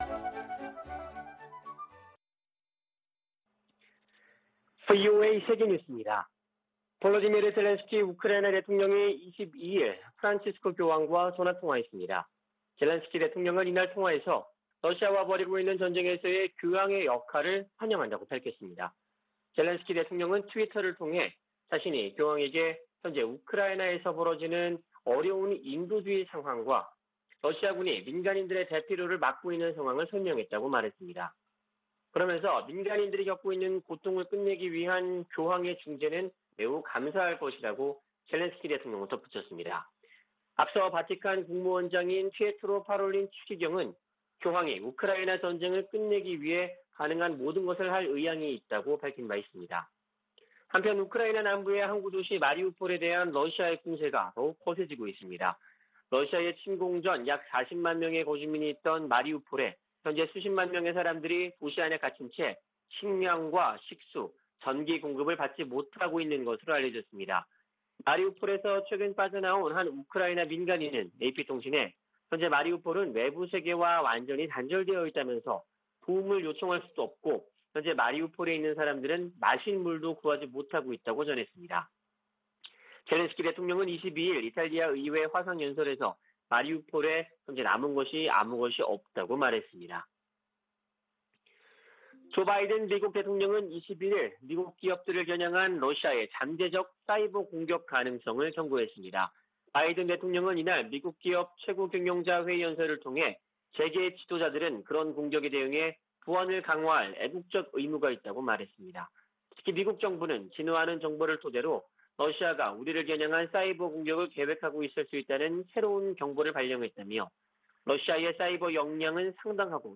VOA 한국어 아침 뉴스 프로그램 '워싱턴 뉴스 광장' 2022년 3월 23일 방송입니다. 북한이 연일 미한 연합훈련을 비난하는데 대해, 이는 동맹의 준비태세를 보장하는 주요 방법이라고 미 국방부가 밝혔습니다.